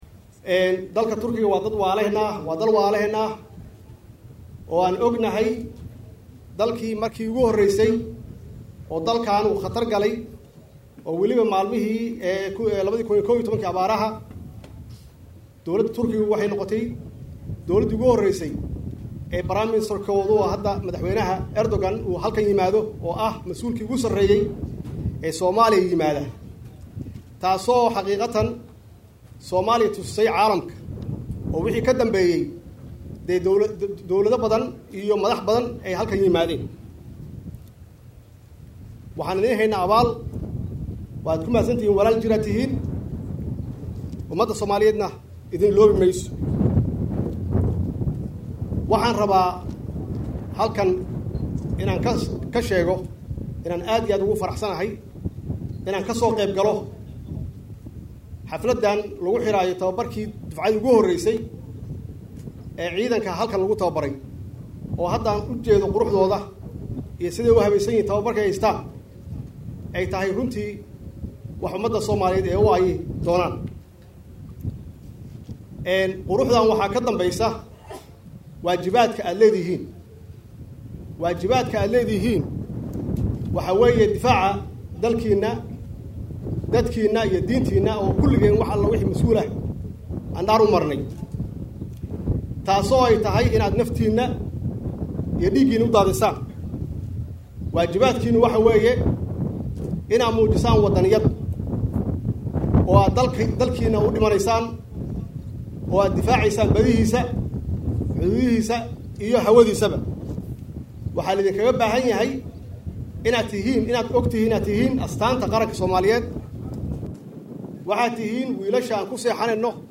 Dhagayso khudbaddii madaxwaynaha uu ka jeediyay saldhigga Turky-Som
Madaxwaynaha ayaa khudbad ka jeediyay halkaasi, isaga oo u mahad ciliyay dowladda Turkiga.